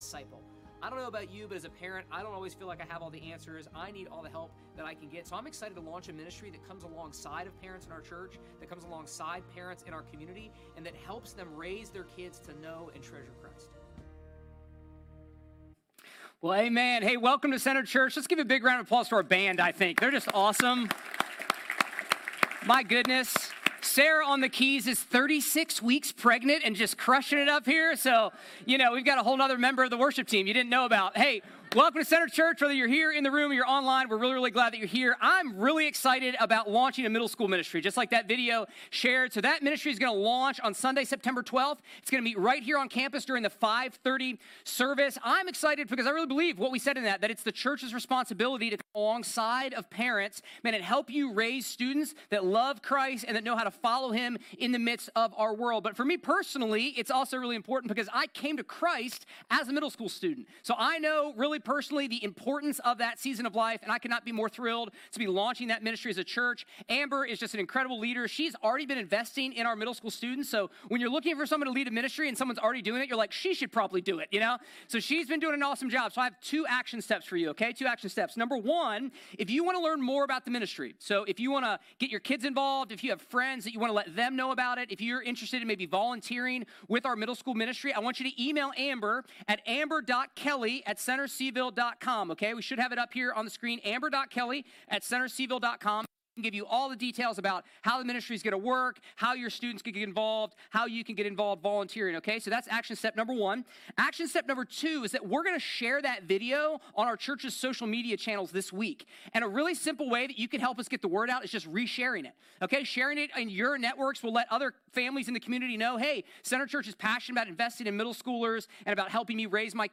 A message from the series "The Gospel of Mark."